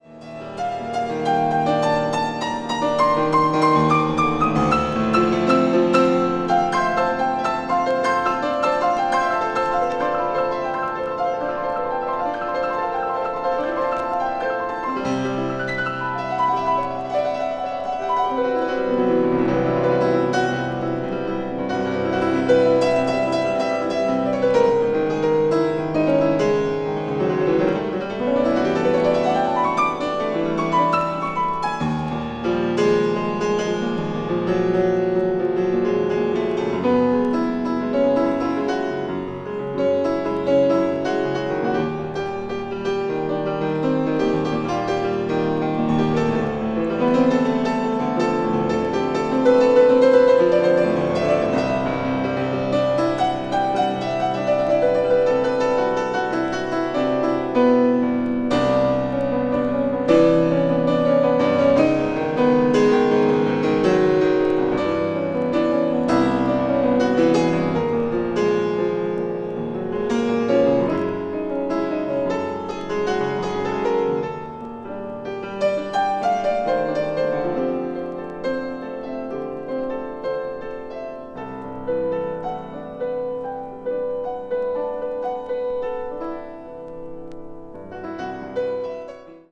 media : EX/EX(わずかにチリノイズが入る箇所あり)
同年2月にパルコ・シアターで行われたコンサートのライブ・レコーディング音源を収録。
ambient   new age   piano solo